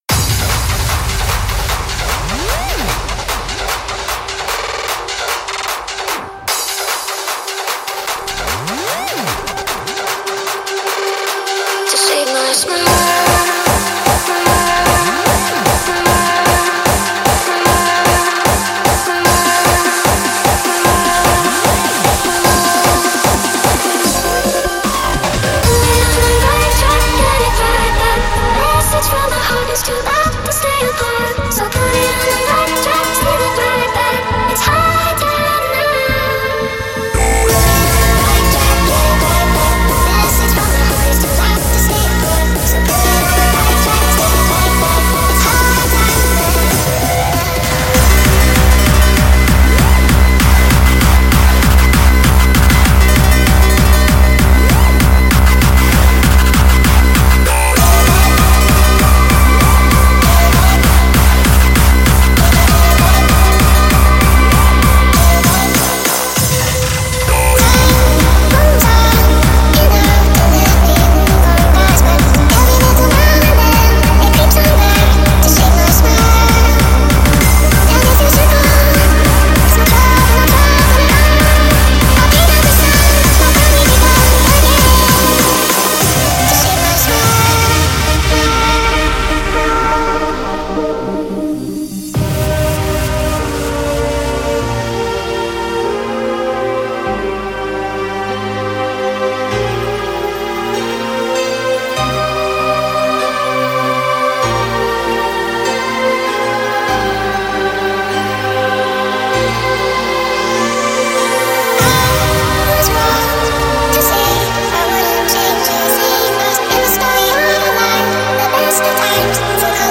Index of: /data/localtracks/Hardstyle/